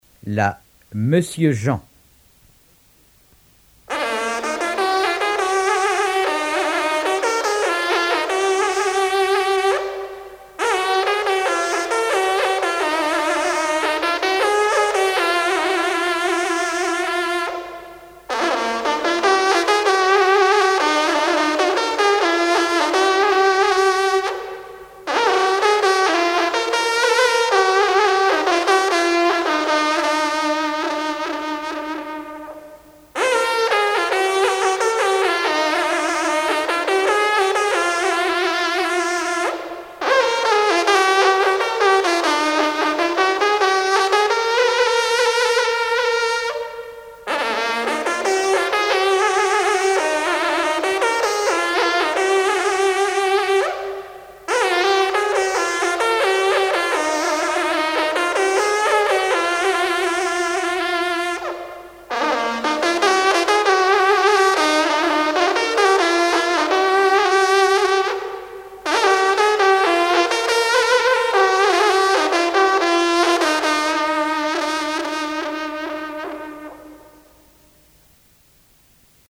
trompe - fanfare
circonstance : vénerie